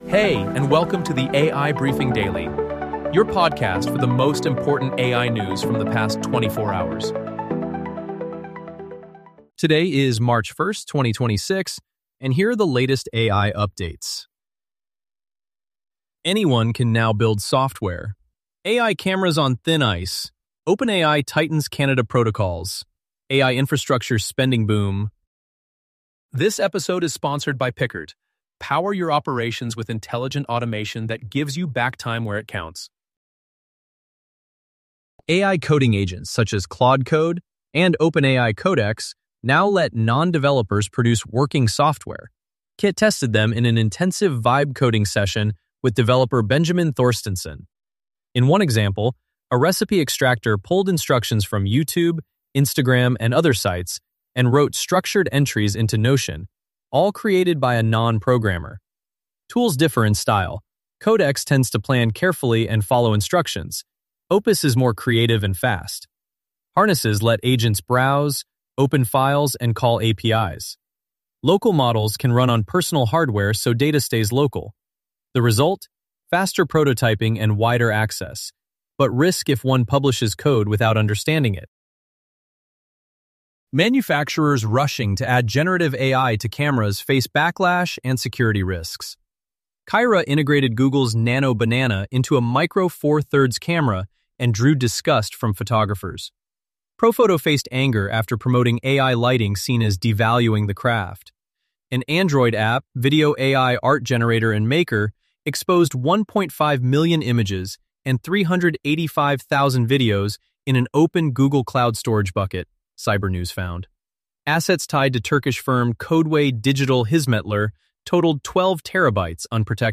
Unique: Our podcast is 100% AI-generated - from research to production to upload.